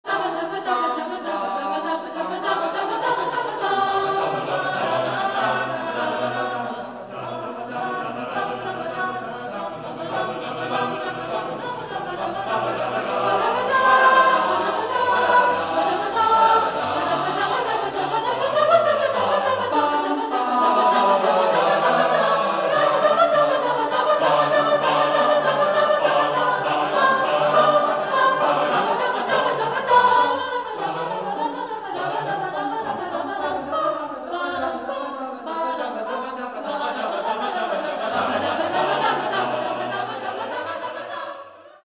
Choral, Classical